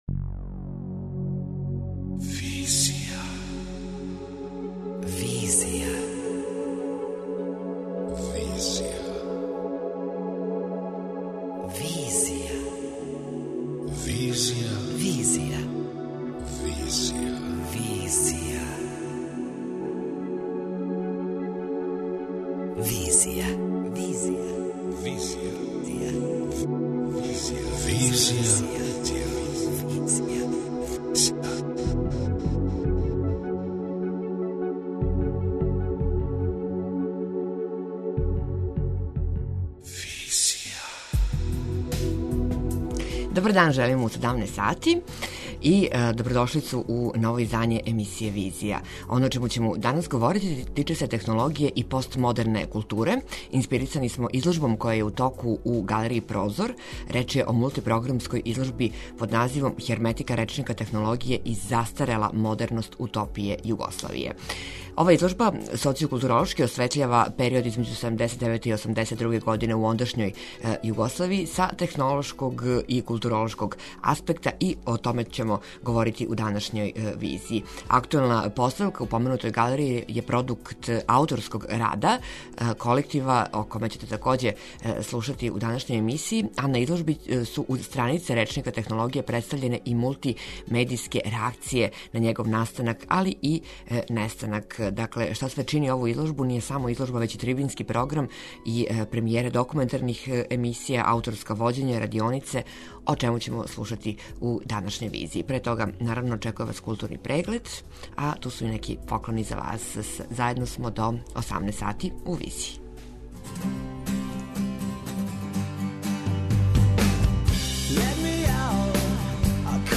преузми : 27.23 MB Визија Autor: Београд 202 Социо-културолошки магазин, који прати савремене друштвене феномене.